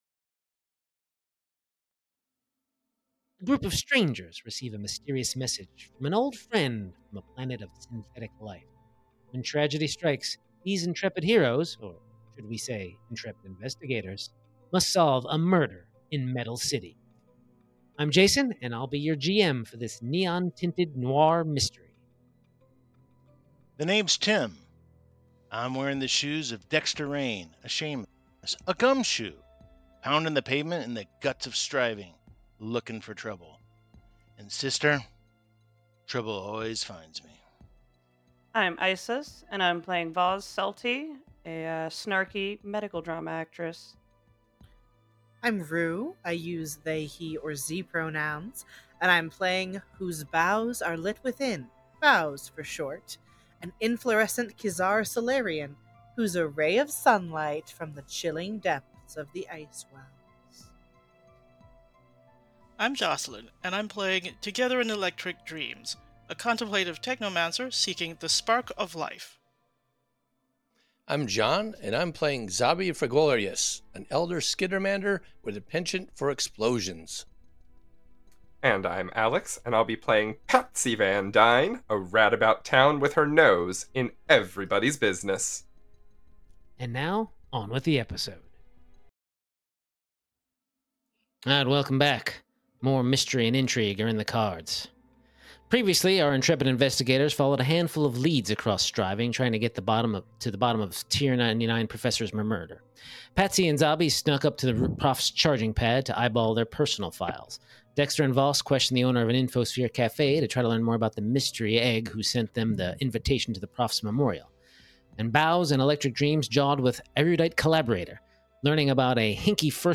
Starfinder 2nd Edition Actual Play Podcast